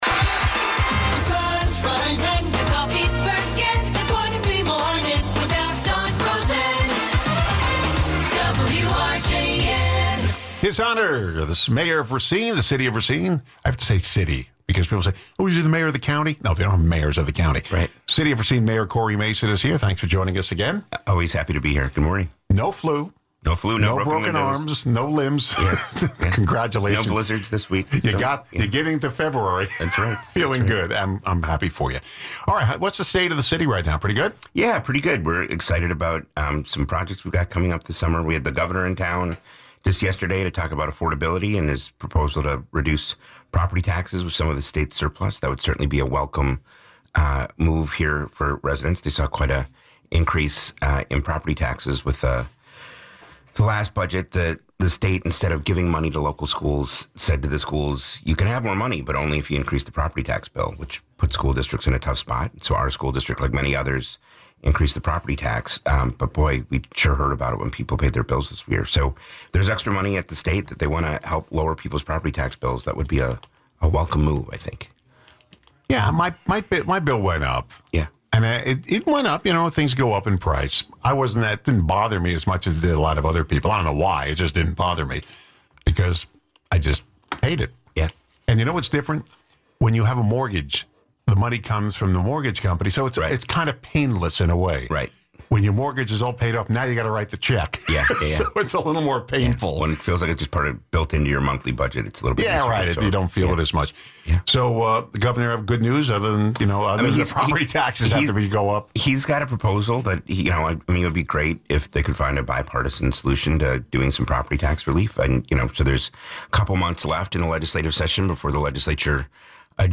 Racine Mayor Cory Mason returns to the show to chat about the state of the city, including an update on property taxes.
Guests: Cory Mason